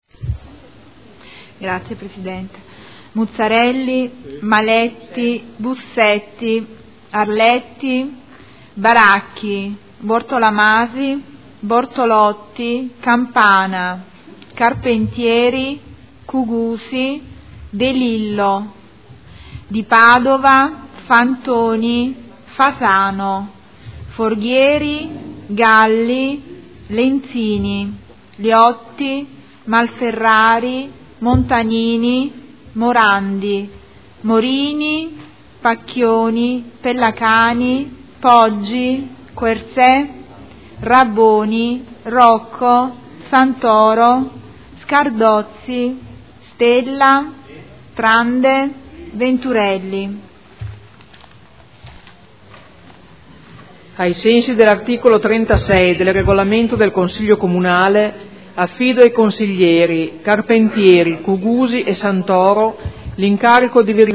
Seduta del 29/01/2015. Appello
Segretario Generale